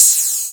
Index of /90_sSampleCDs/Club_Techno/Percussion/Cymbal
Ride_10.wav